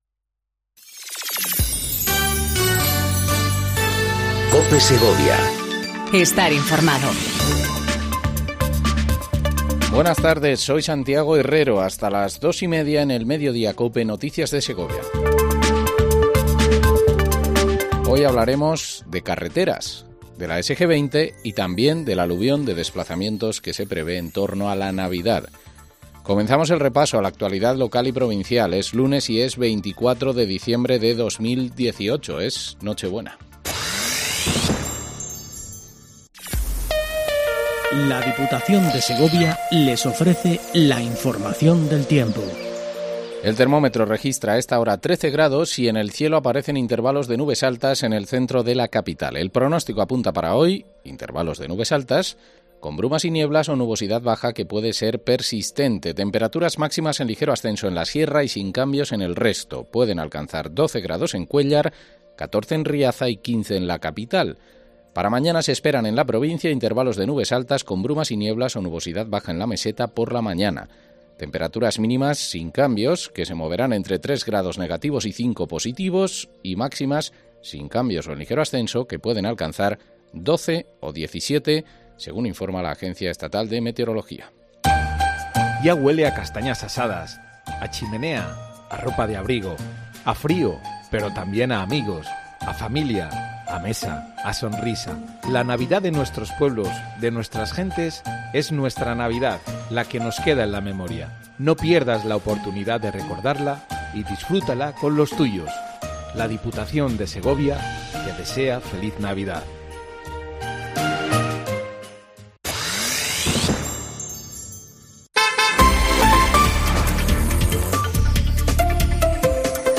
INFORMATIVO MEDIODÍA EN COPE SEGOVIA 14:20 DEL 24/12/18